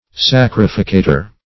sacrificator - definition of sacrificator - synonyms, pronunciation, spelling from Free Dictionary
Search Result for " sacrificator" : The Collaborative International Dictionary of English v.0.48: Sacrificator \Sac"ri*fi*ca`tor\, n. [L.] A sacrificer; one who offers a sacrifice.